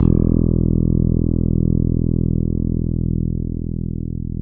KT 88 FINGER.wav